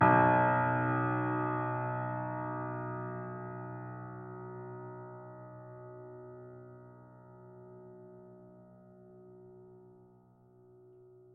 piano-sounds-dev
Steinway_Grand
b0.mp3